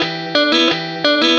Track 02 - Guitar Lick 10.wav